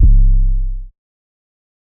808 [Juicy].wav